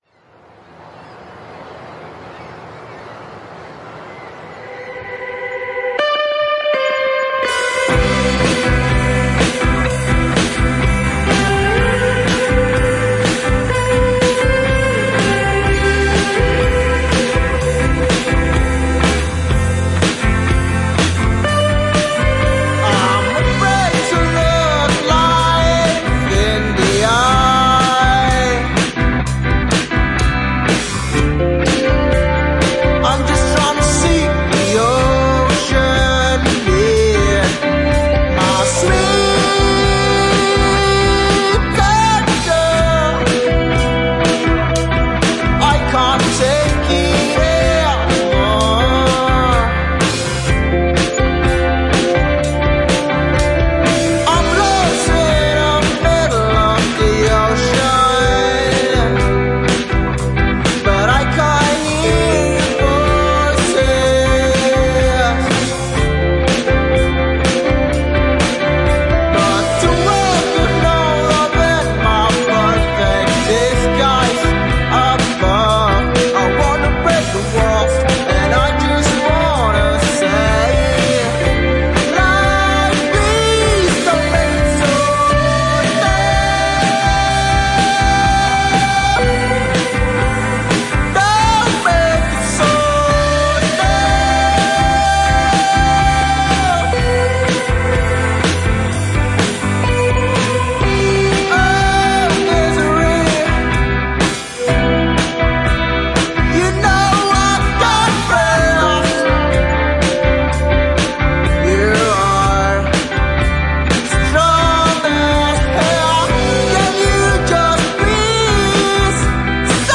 Bogor Alternative
termasuk Pop dan Rock.